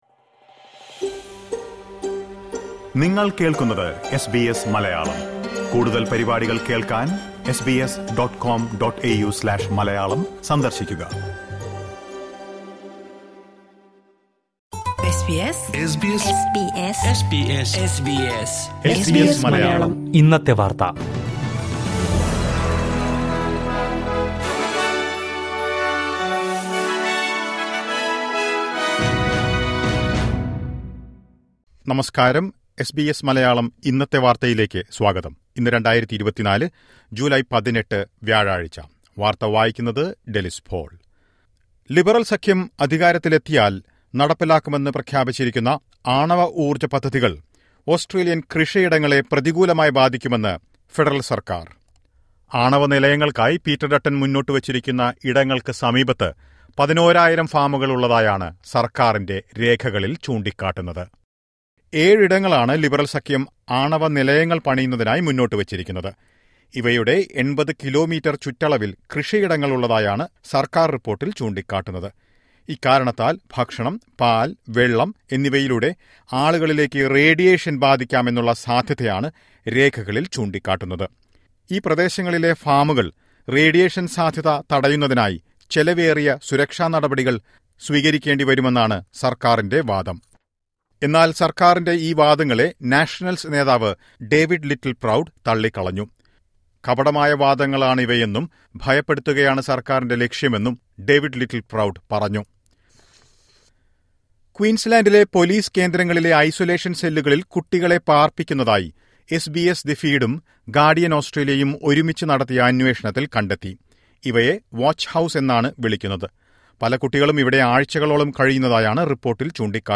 2024 ജൂലൈ 18ലെ ഓസ്‌ട്രേലിയയിലെ ഏറ്റവും പ്രധാന വാര്‍ത്തകള്‍ കേള്‍ക്കാം...